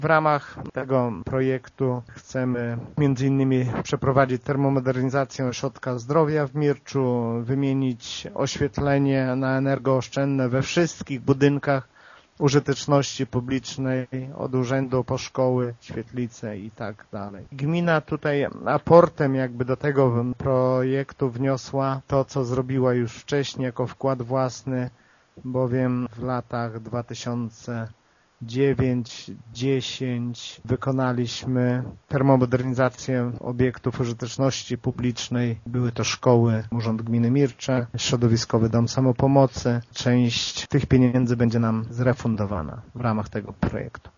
Wójt Lech Szopiński cieszy się z tak wysokiej oceny specjalistów i podkreśla, że będą to kolejne działania termomodernizacyjne w gminie Mircze: